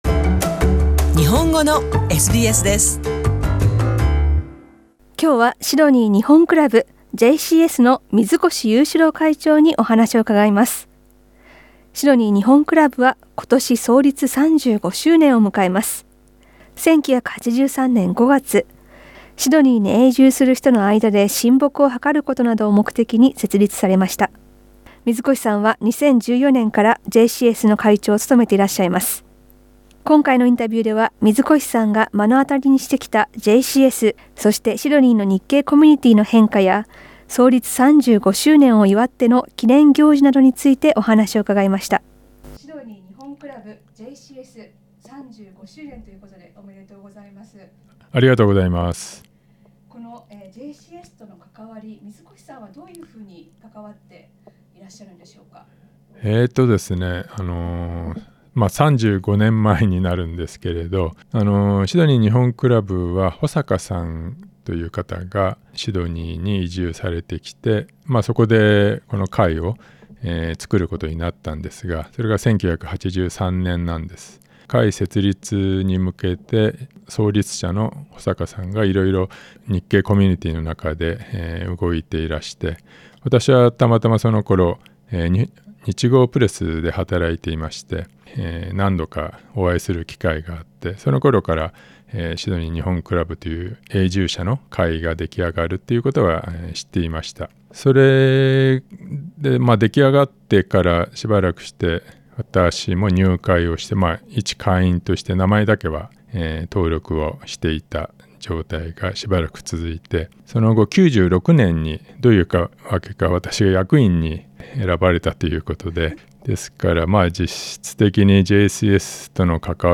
インタビューでは、JCSや日系コミュニティーの変化について、そして創立35周年記念イベントなどについてお話を伺いました。